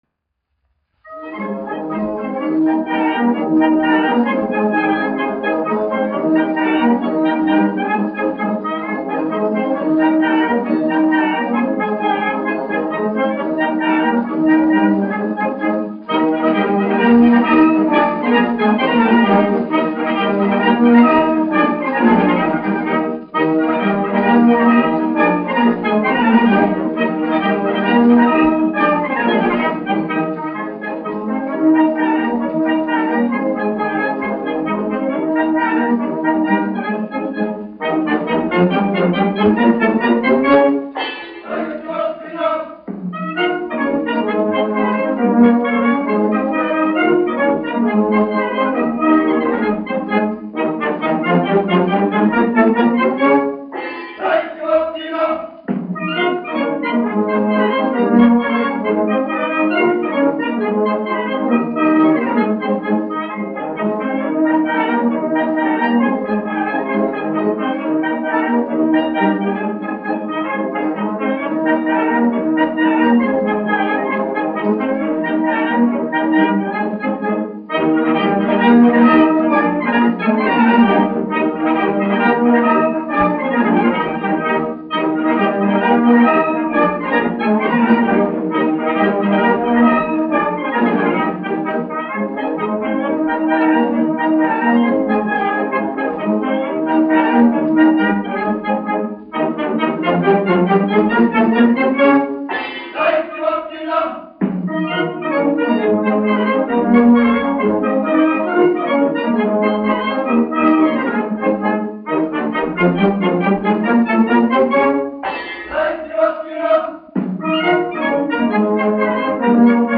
1 skpl. : analogs, 78 apgr/min, mono ; 25 cm
Polkas
Pūtēju orķestra mūzika
Skaņuplate